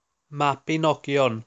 The Mabinogion (Welsh pronunciation: [mabɪˈnɔɡjɔn]